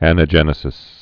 (ănə-jĕnĭ-sĭs)